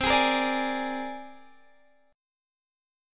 C7sus2#5 chord